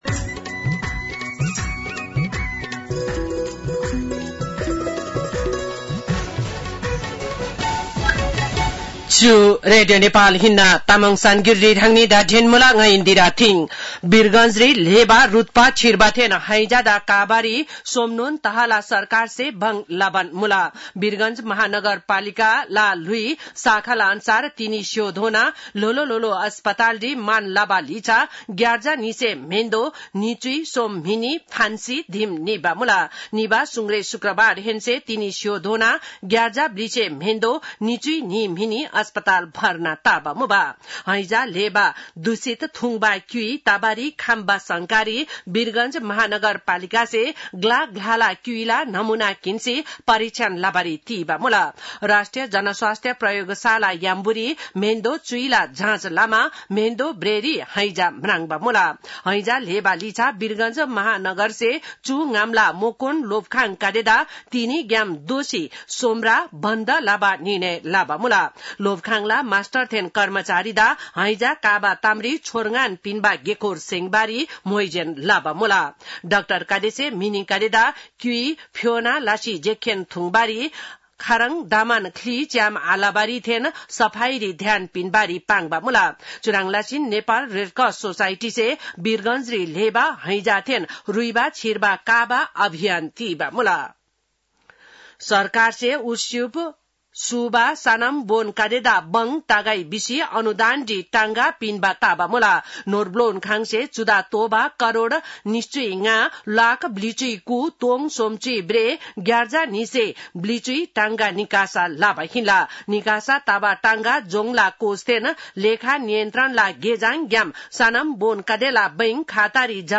तामाङ भाषाको समाचार : ११ भदौ , २०८२